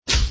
XN_hurt.mp3